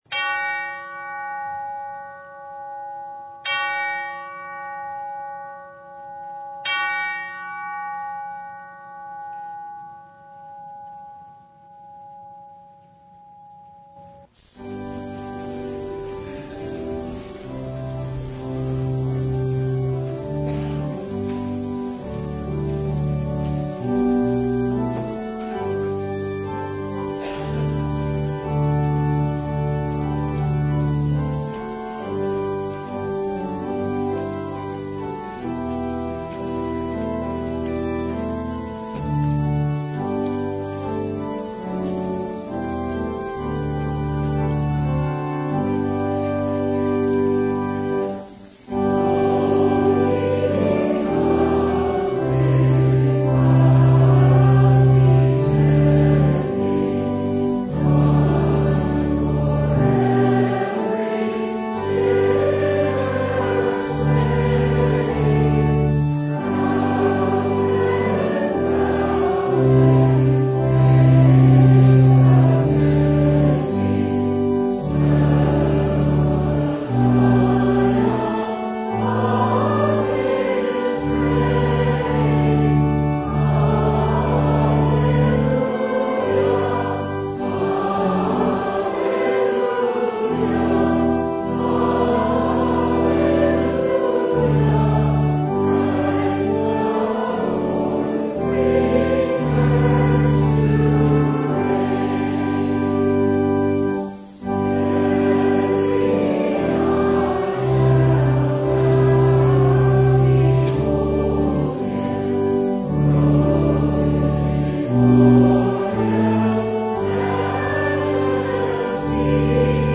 Download Files Bulletin Topics: Full Service « Thanksgiving Eve (2022) Behold, Your King Comes!